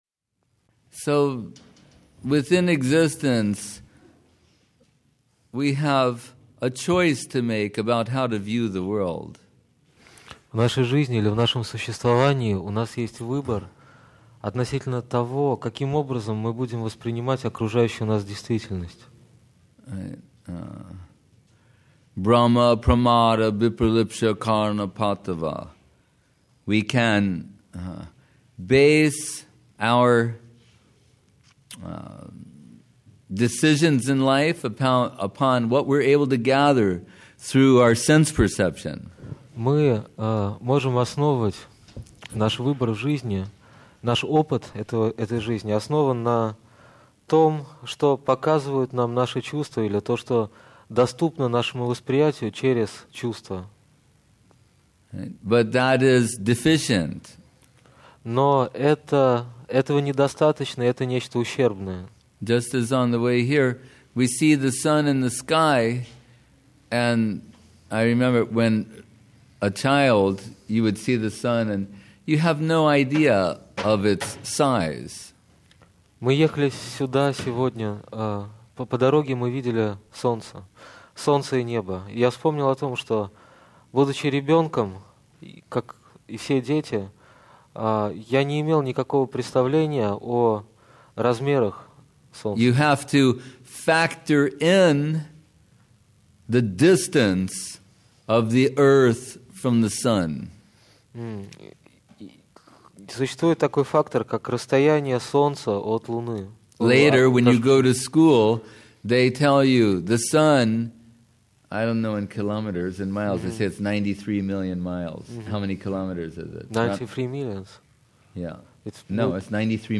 Place: Sri Chaitanya Saraswat Math Saint-Petersburg